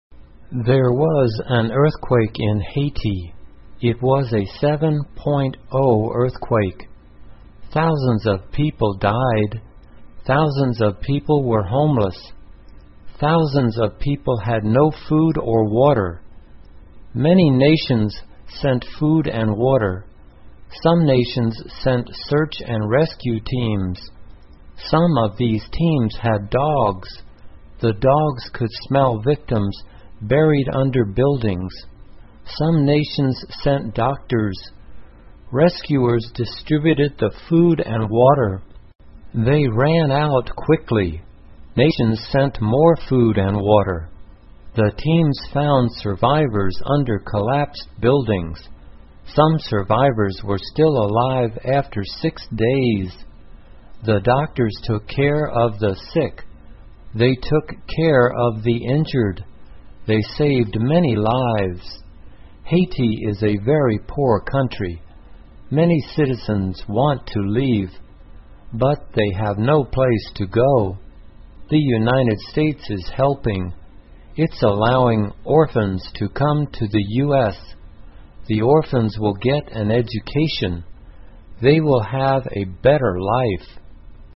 慢速英语短文听力 海地地震 听力文件下载—在线英语听力室